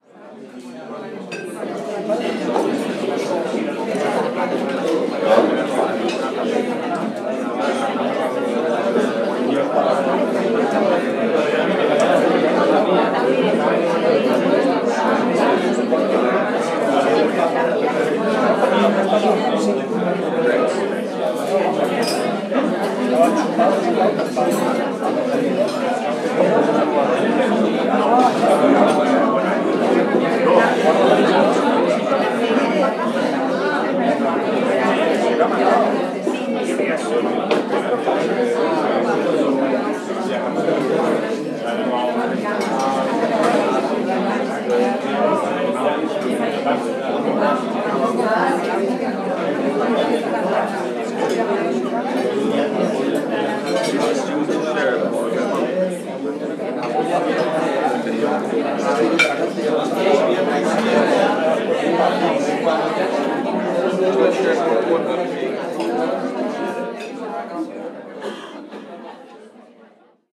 Ambiente de un restaurante español